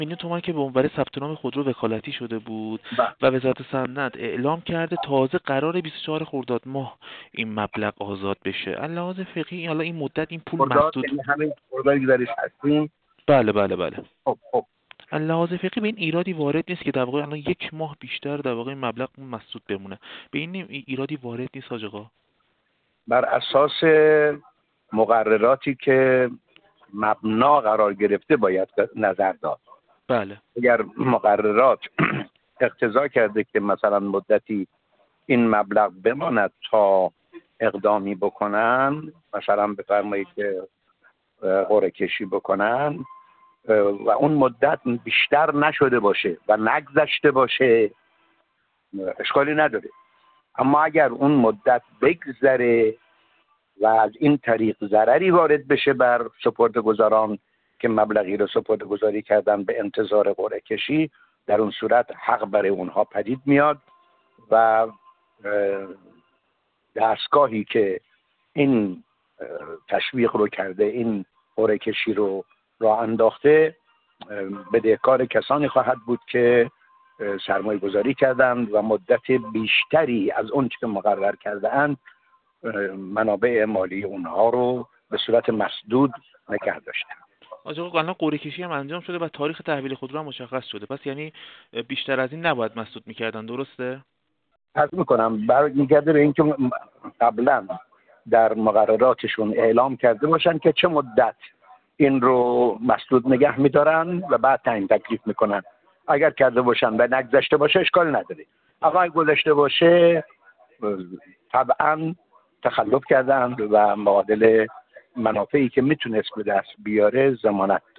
حجت‌الاسلام و المسلمین غلامرضا مصباحی‌مقدم، رئیس شورای فقهی بانک مرکزی در گفت‌وگو با ایکنا، درباره وکالتی کردن حساب بانکی با هدف ثبت‌نام در سامانه فروش یکپارچه خودرو و مسدود شدن یکصد میلیون تومان پول متقاضیان به مدت یک ماه و حتی بیشتر اظهار کرد: وکالتی کردن حساب ایراد فقهی ندارد و در این زمینه باید براساس مقرراتی که مبنا قرار گرفته نظر داد.